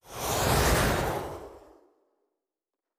Sci-Fi Sounds / Weapons / Weapon 11 Shoot 2 (Rocket Launcher).wav
Weapon 11 Shoot 2 (Rocket Launcher).wav